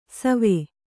♪ save